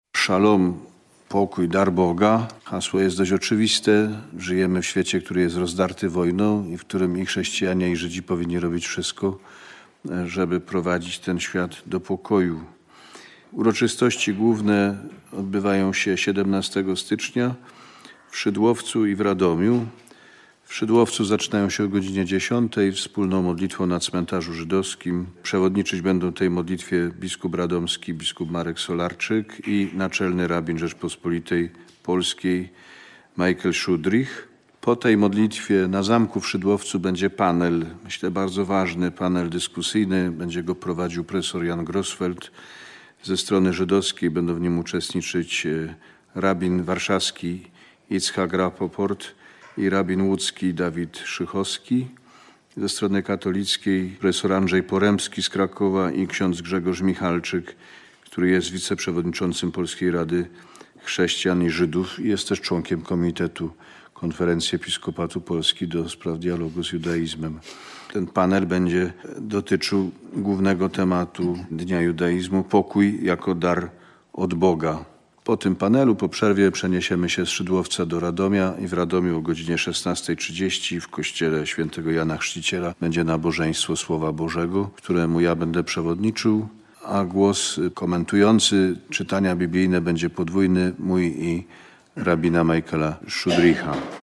Kardynał wyjaśnia, jak wyglądają tegoroczne centralne obchody Dnia Judaizmu w Kościele Katolickim w Polsce.